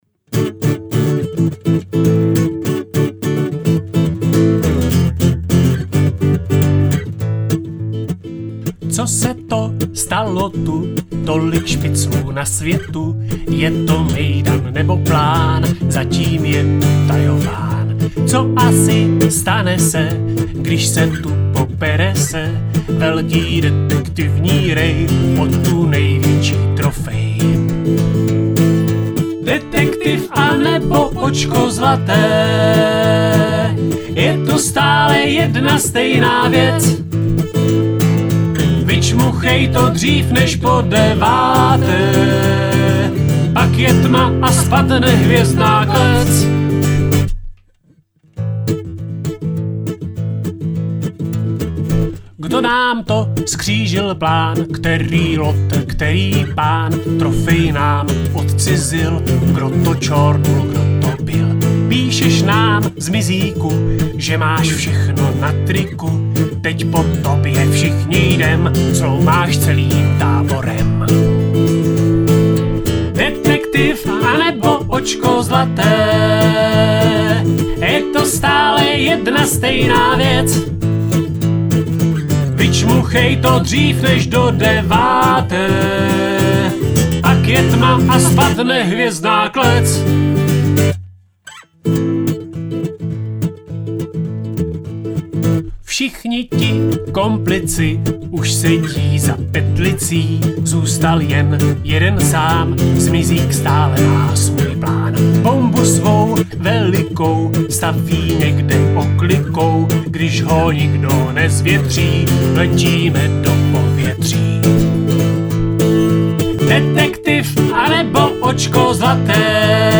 Každý rok máme pro táborníky připravenou hymnu, která je složena na téma táborové hry. Zpívá se na začátku každého táborového ohně. Zpravidla ji umí zpívat všechny děti, protože je vždy textově i hudebně jednoduchá.